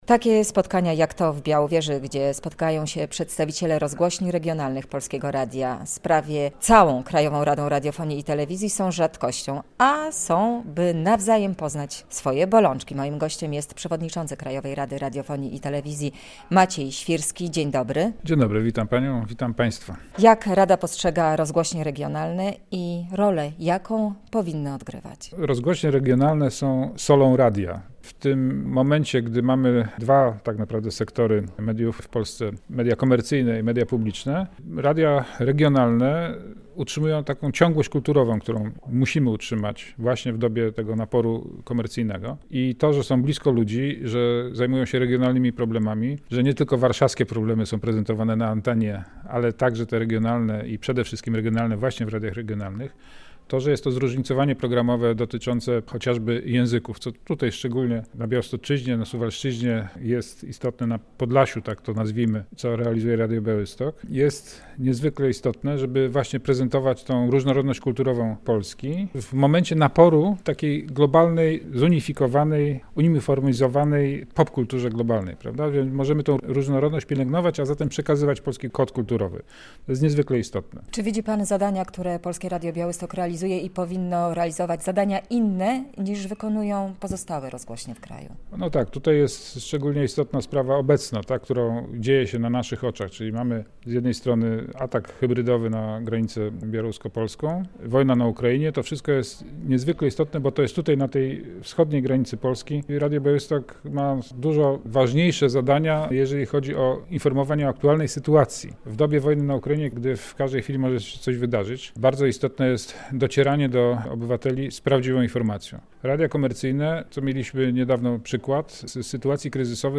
Radio Białystok | Gość | Maciej Świrski [wideo] - przewodniczący Krajowej Rady Radiofonii i Telewizji
przewodniczący Krajowej Rady Radiofonii i Telewizji